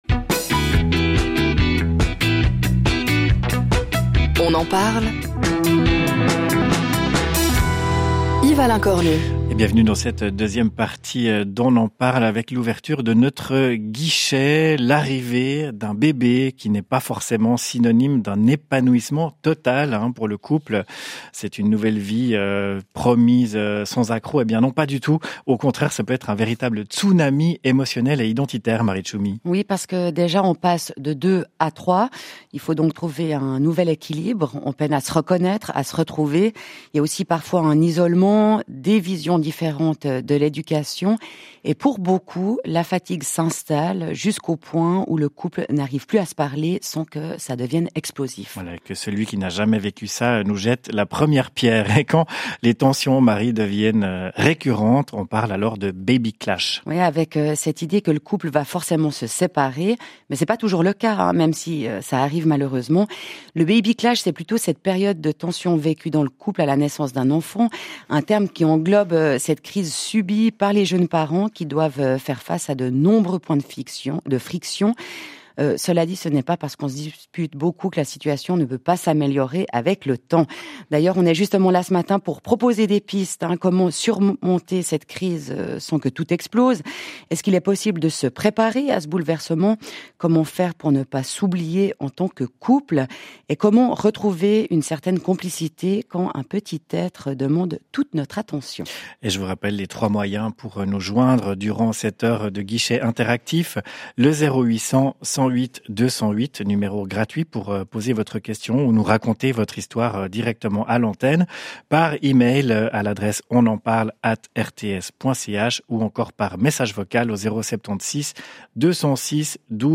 émission radio phare de la RTS